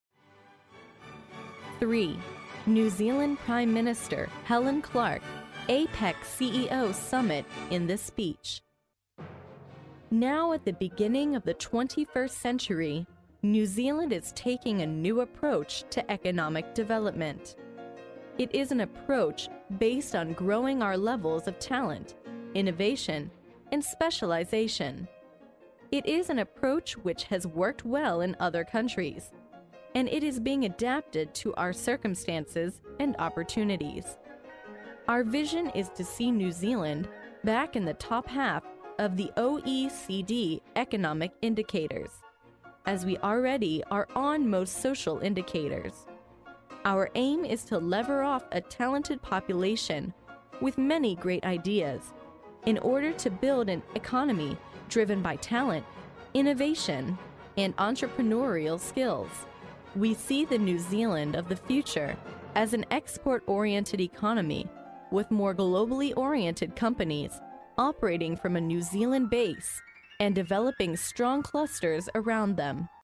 历史英雄名人演讲 第54期:新西兰总理海伦克拉克在APECCEO峰会上的演讲(1) 听力文件下载—在线英语听力室